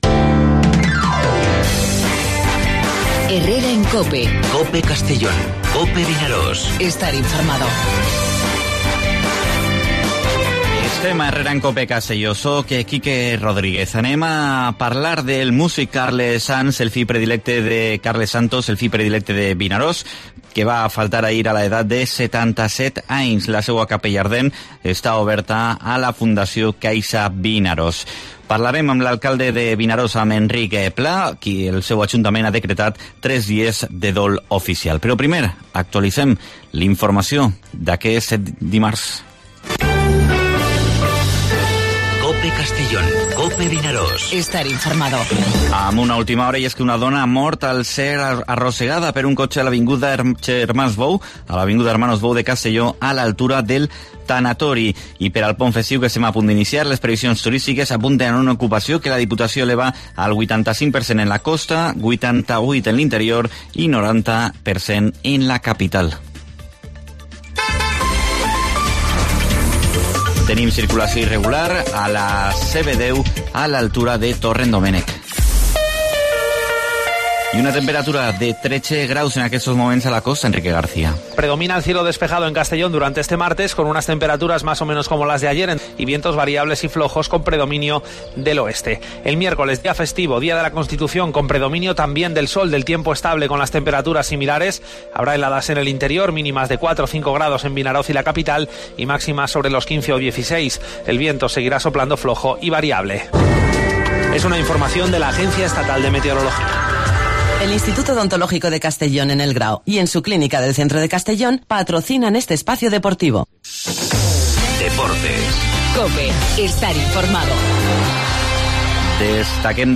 A Herrera en COPE, Vinaròs que està de dol per la mort del mùsic Carles Santos. Parlem amb l'alcalde, Enric Pla, que ha decretat tres dies de dol oficial en memòria del Fill Predilecte de la població; I a Mediodía COPE analitzem el pont festiu per al turisme i l'ocupació que s'espera els propers dies amb l'alcalde de Peniscola i vicepresident de la Diputació, Andres Martinez.